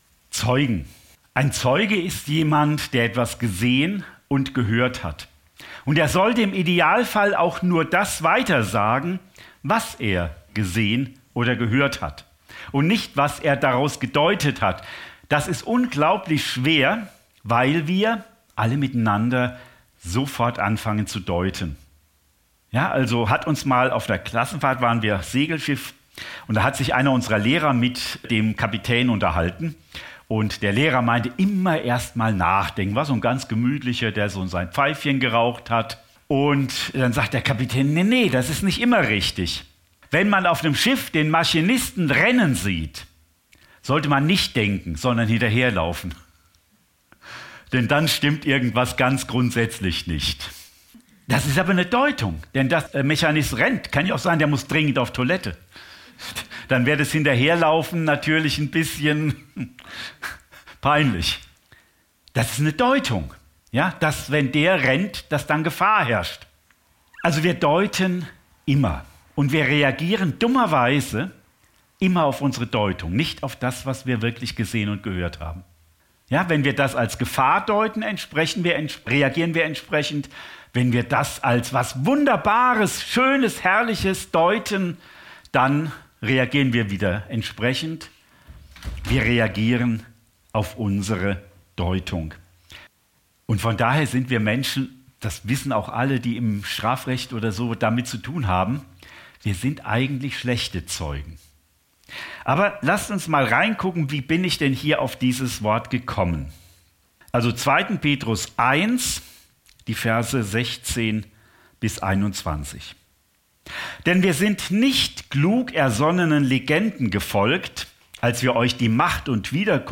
Predigt vom 03. November 2024 – Süddeutsche Gemeinschaft Künzelsau